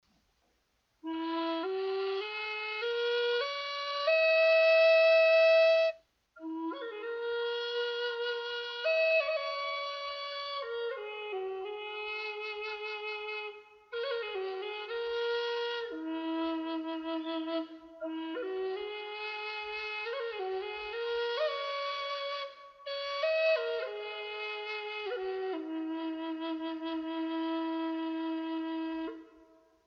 Western Cedar Native American Flutes
This is one of my favorite woods to make Native American Flutes out of because it is the most "mellow" sounding of all! 5 Hole Western Cedar Flues are also great Native American Flutes for beginners because the wood is so "forgiving" of the playing styles of new flute players.